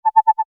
Wecktöne